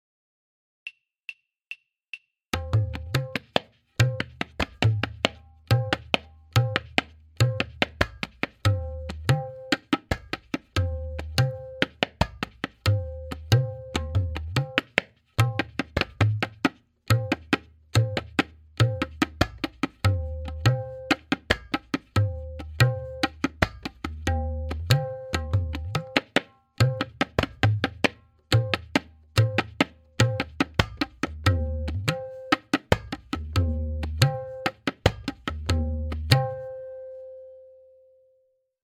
Note: The following forms are all played in dugun (2:1).
Farmaishi Chakradar
M8.5-Farmaishi-Click.mp3